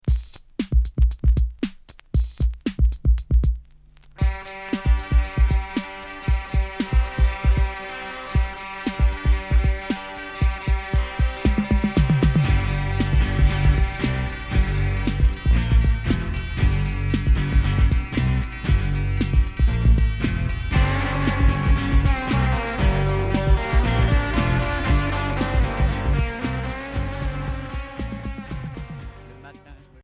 Minimal wave garage